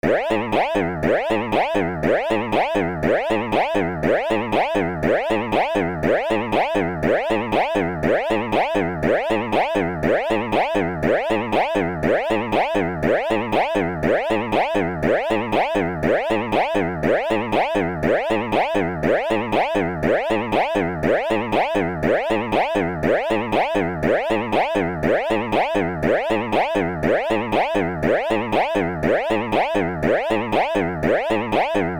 Boingity Boing
cartoon-sound funny sound-effect spring sound effect free sound royalty free Sound Effects